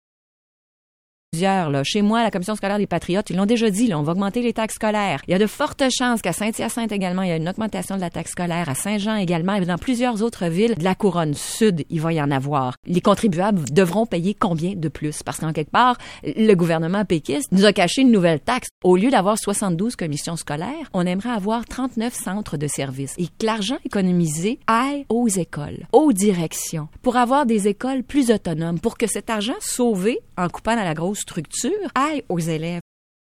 En visite dans les studios de BOOM-FM, la députée de Montarville Nathalie Roy discute de la hausse probable des taxes scolaires.
entrevue24janvier.mp3